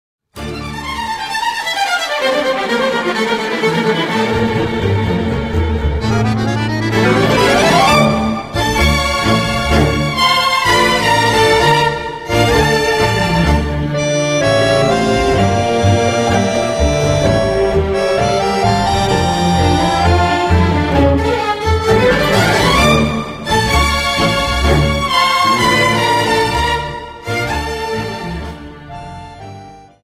Dance: Tango Song